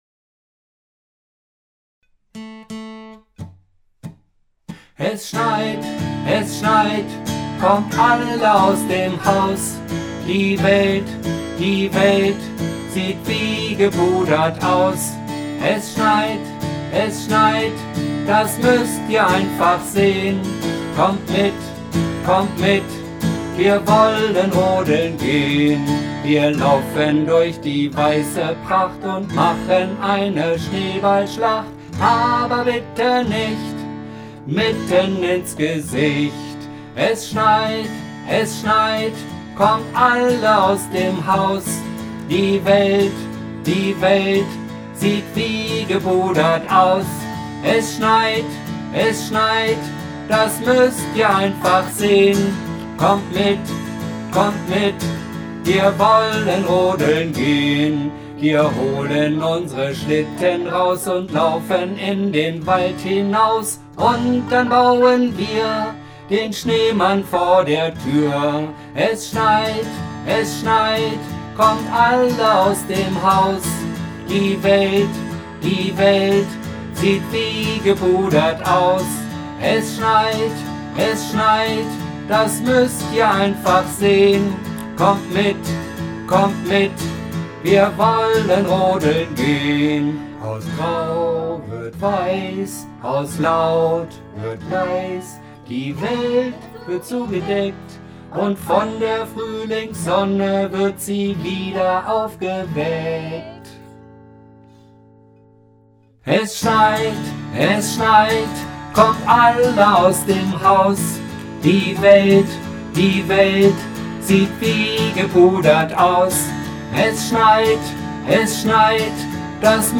( Gitarre + Gesang (mehrfach). Links und rechts oktaviert, dafür Formanten -40%)